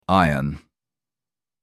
Najpierw przeczytaj głośno dany wyraz, a potem posłuchaj prawidłowej wymowy.
Na wymowę w  szkołach nie kładzie się nacisku, dlatego często nawet nie zdajemy sobie sprawy, że słowa, które wydają nam się oczywiste, wymawiamy błędnie (żelazko to nie "ajron")!
iron-br.mp3